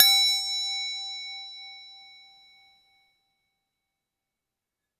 Goblet_G_Loud
bell chime ding dong goblet instrument ping sound effect free sound royalty free Music